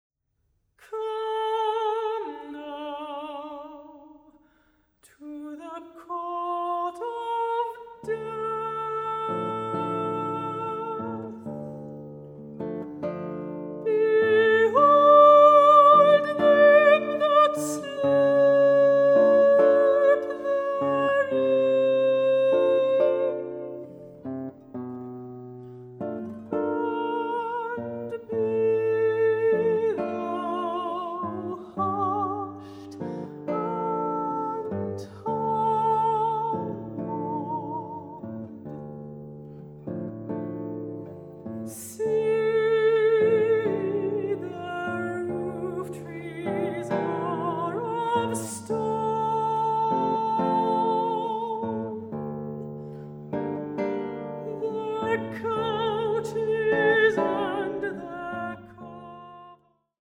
guitar
mezzo-soprano